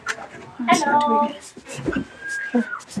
Audio Capture (EVP-Spirit Box)
EVP 1 Girl Screaming